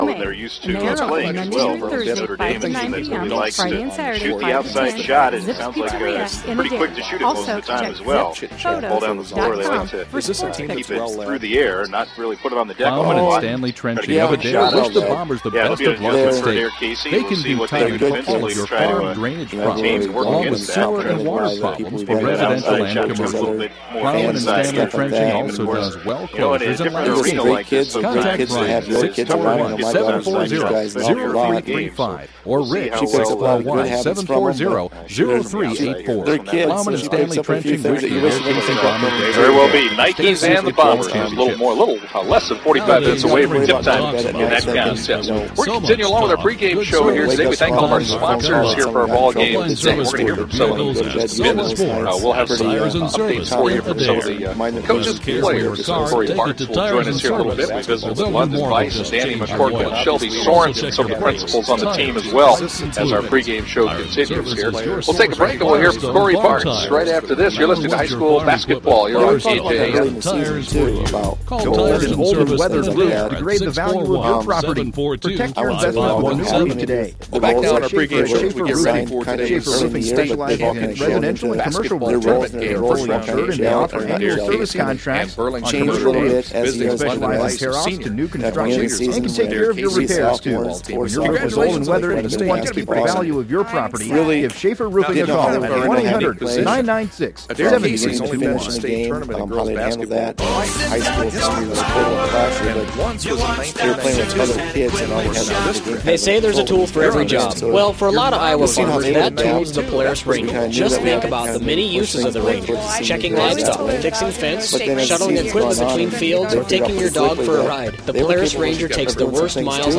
from Wells Fargo Arena in Des Moines